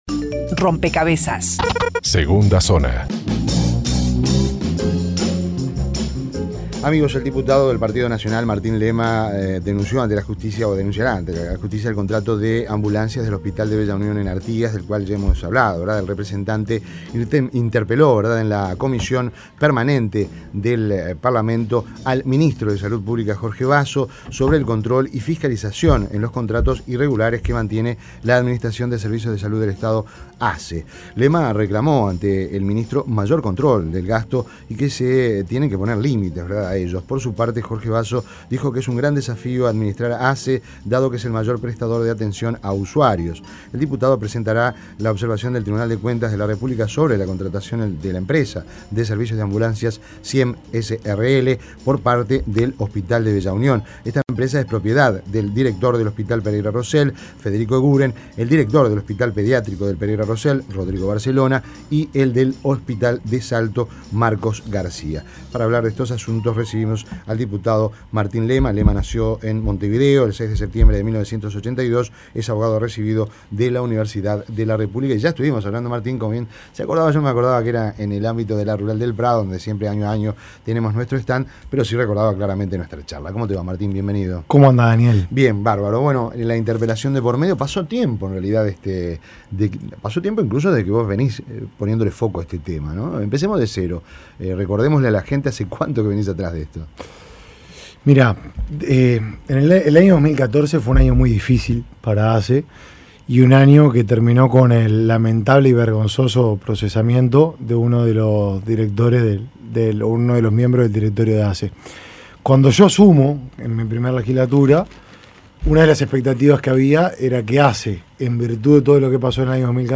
Entrevista en Rompkbzas Sistema Nacional Integrado de Amigos Dip.